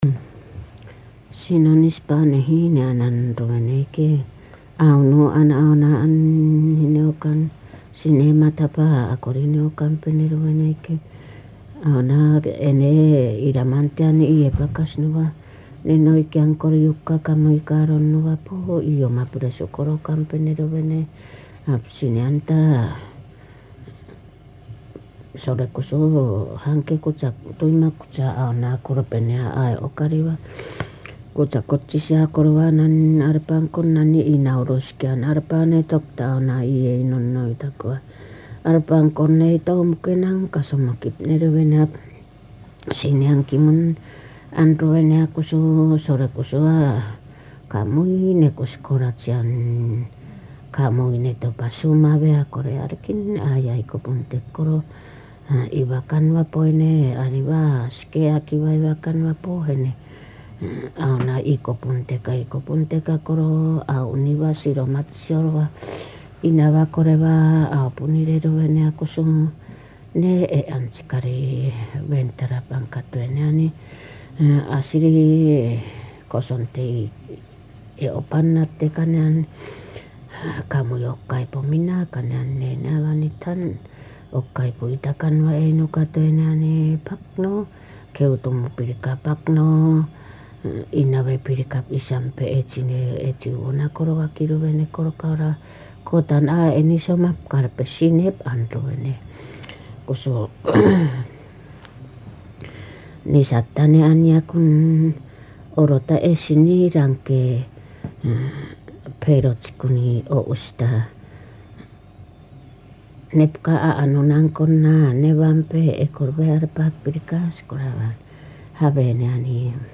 [4-2 散文説話 prose tales] アイヌ語音声 11:53